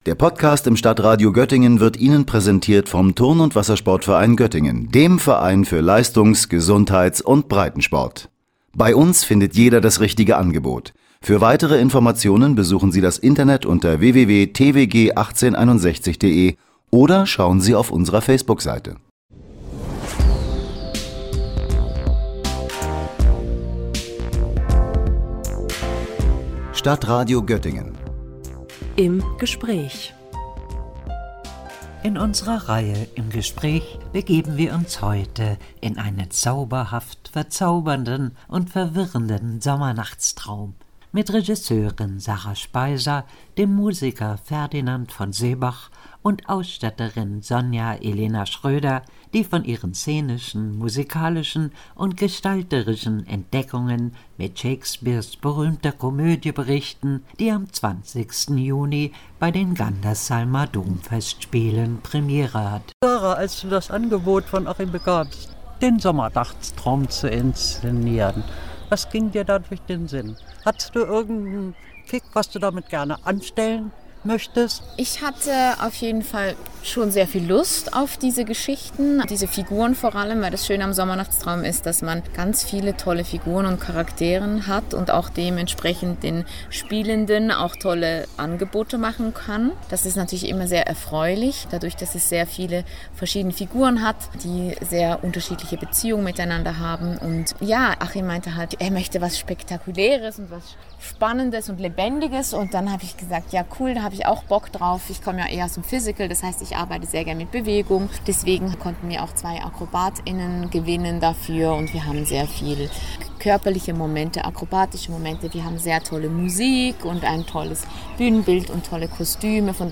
Shakespeares „Sommernachtstraum“ zum Auftakt der Gandersheimer Domfestspiele – Gespräch